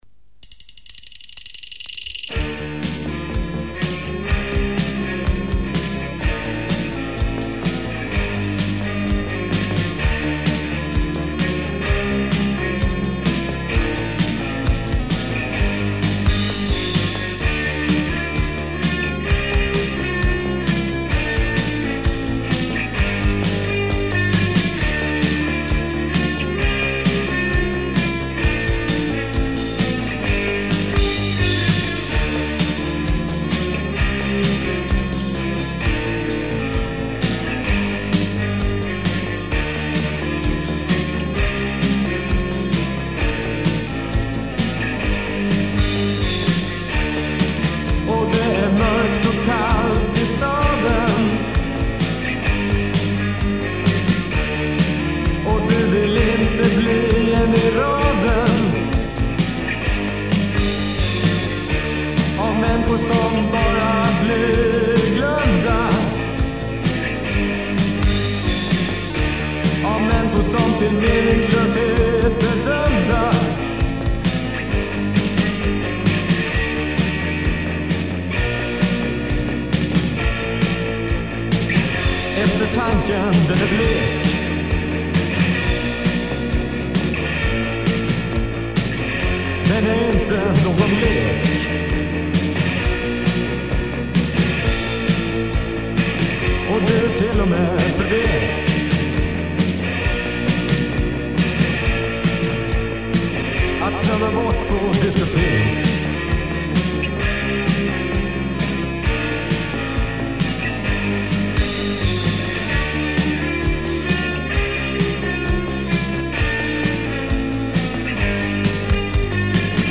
AKG D12 Used for bass drum